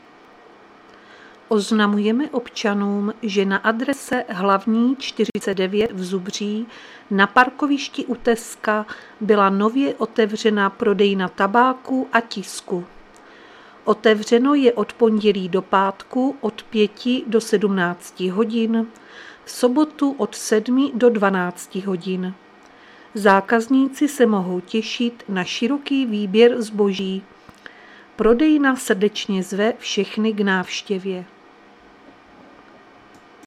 Záznam hlášení místního rozhlasu 2.6.2025